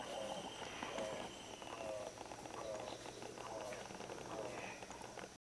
Arasarí Chico (Selenidera maculirostris)
Nombre en inglés: Spot-billed Toucanet
Fase de la vida: Adulto
Localidad o área protegida: Bio Reserva Karadya
Condición: Silvestre
Certeza: Vocalización Grabada